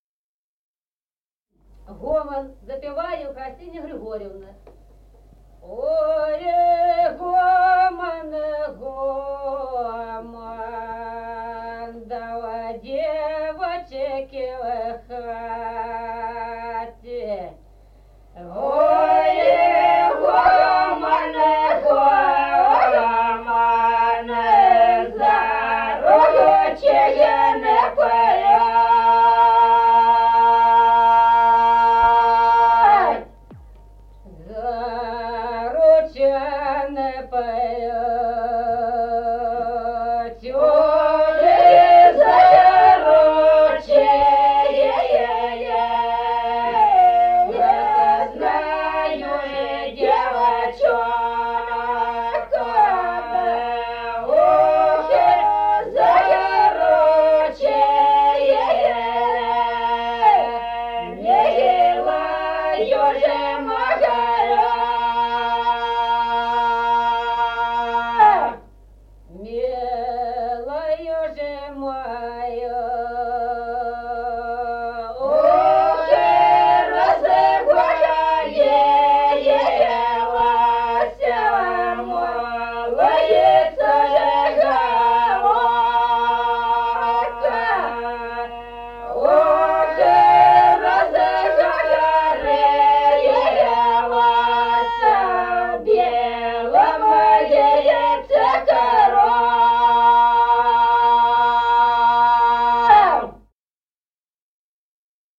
Народные песни Стародубского района «Ой, гомон, гомон», лирическая.
1953 г., с. Остроглядово.